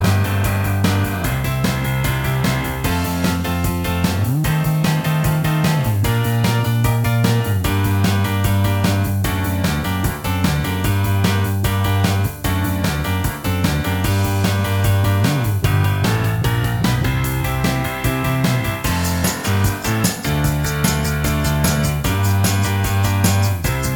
Minus Lead Guitar Rock 3:44 Buy £1.50